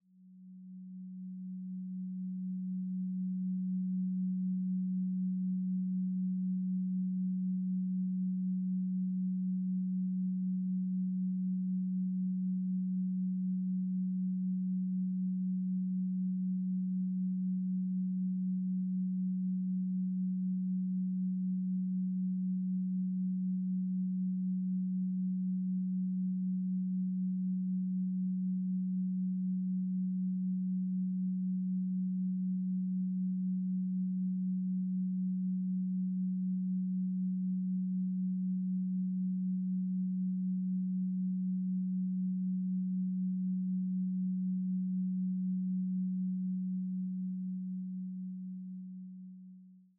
Duration: 0:50 · Genre: Synthwave · 128kbps MP3